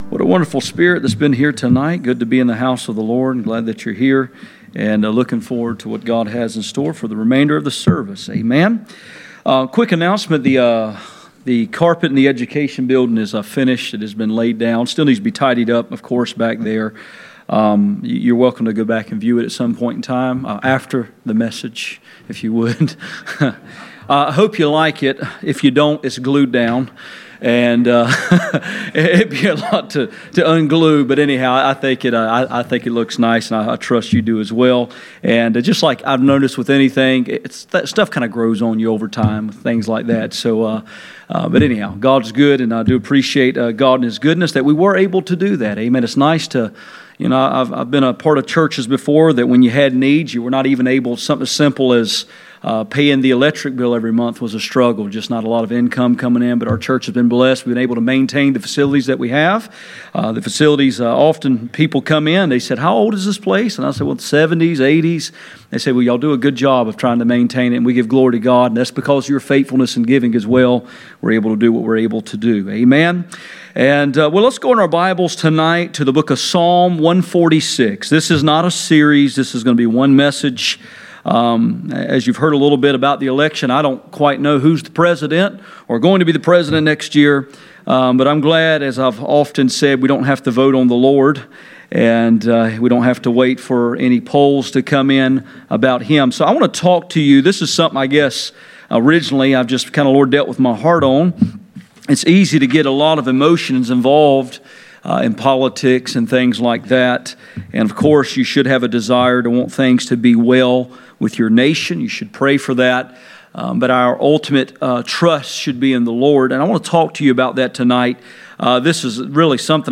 Passage: Psalm 27:7-8 Service Type: Sunday Evening